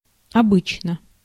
Ääntäminen
IPA : /d͡ʒɛnɹəliː/